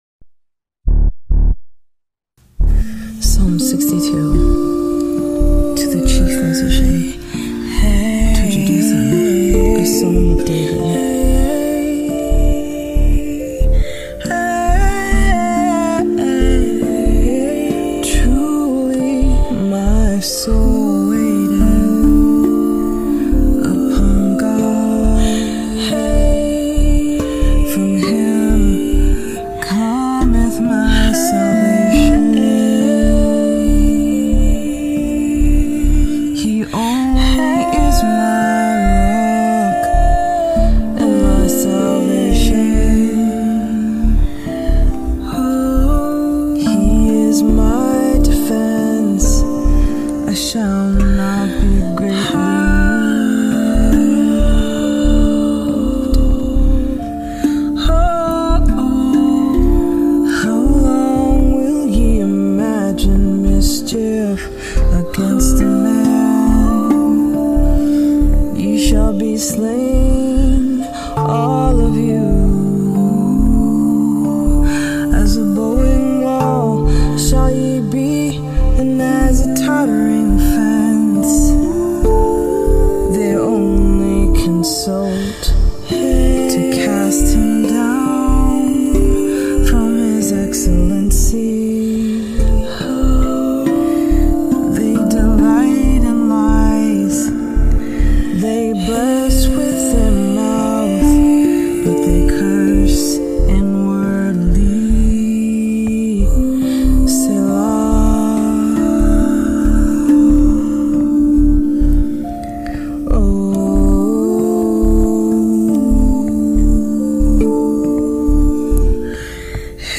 Rav vast drum
tongue drum